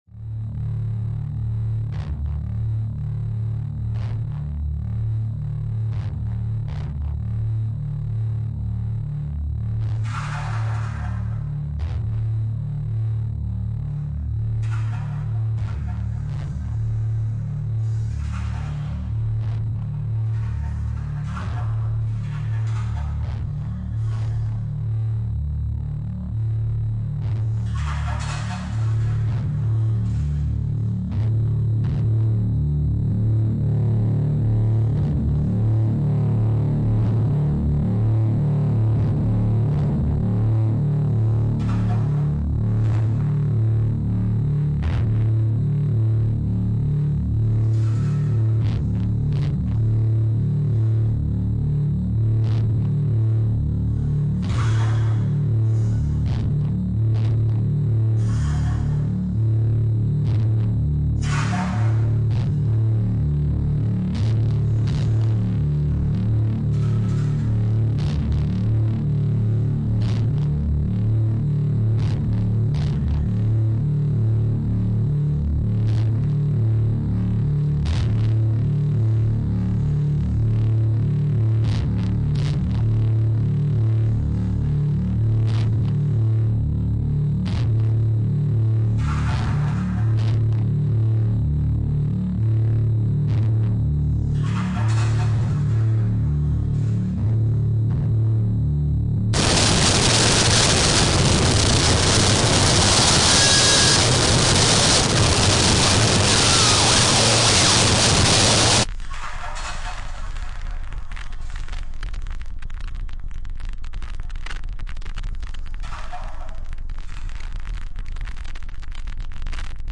Do not adjust your stereo, it's all about the contrasts.